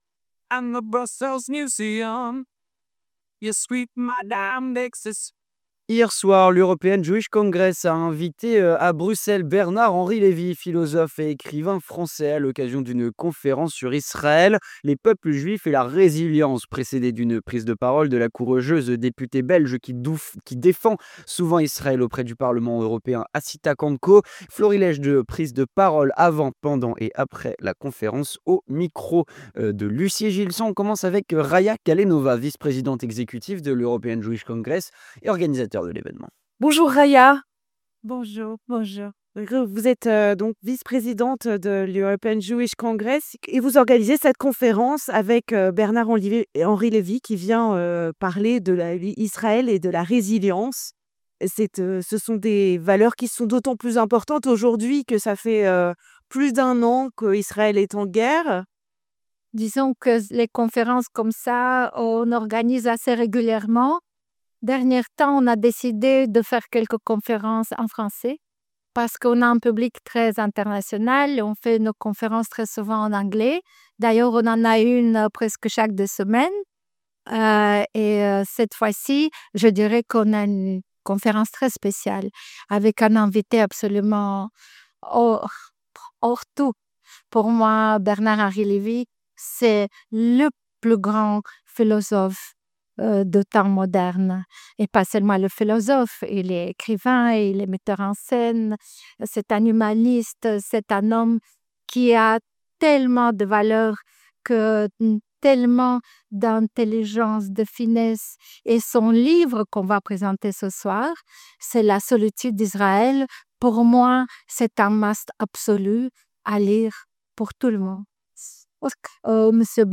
Reportage lors de la conférence de Bernard Henri Levy à Bruxelles (15/11/24)